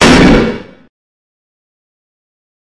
数字科幻 " Digi Talk
描述：胡言乱语
标签： 数字 FX 苛刻
声道立体声